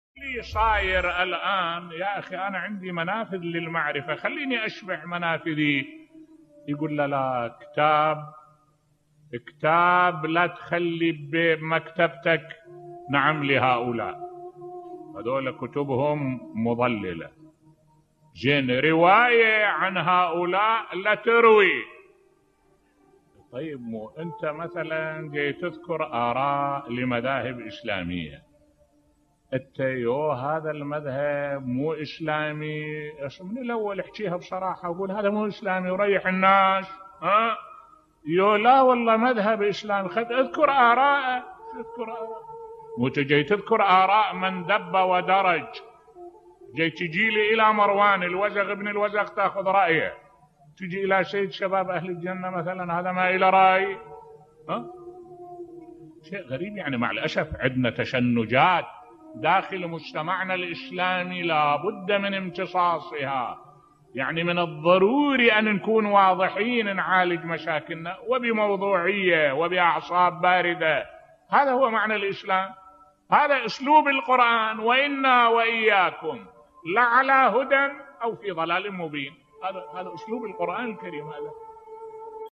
ملف صوتی التشنجات في المجتمع الاسلامي تؤدي إلى تمزيقه بصوت الشيخ الدكتور أحمد الوائلي